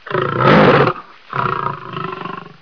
دانلود صدای حیوانات جنگلی 34 از ساعد نیوز با لینک مستقیم و کیفیت بالا
جلوه های صوتی